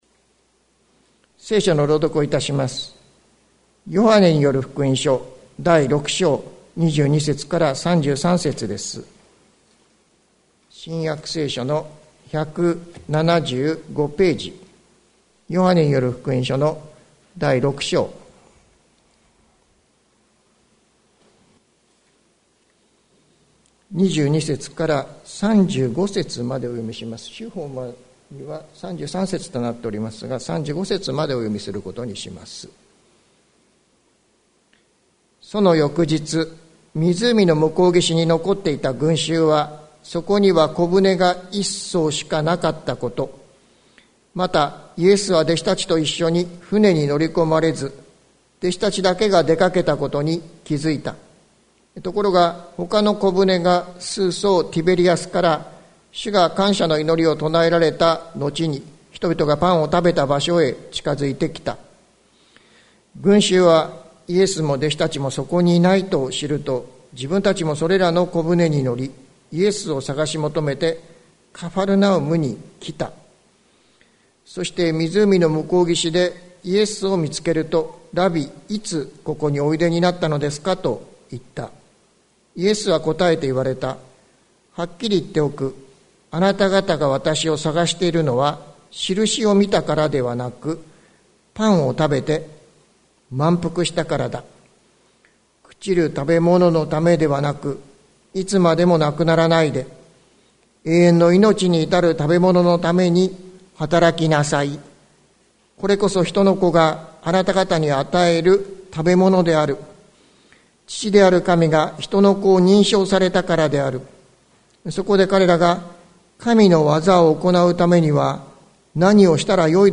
関キリスト教会。説教アーカイブ。